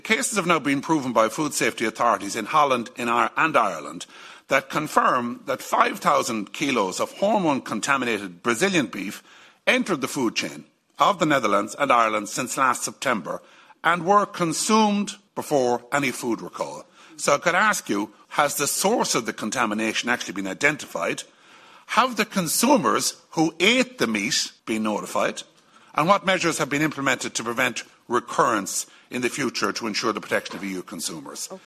MEP Ciaran Mulllooly was speaking during the AGRI Committee in Brussels earlier this week.